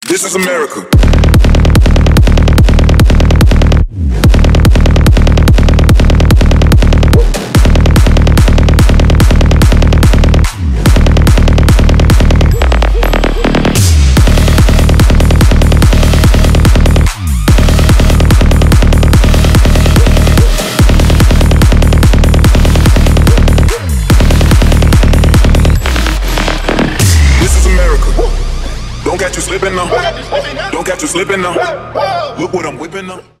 Ремикс # Электроника
громкие